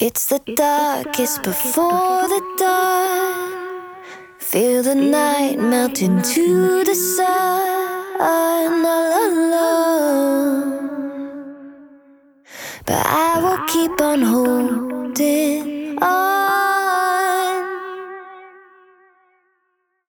Vocals with Mod Delay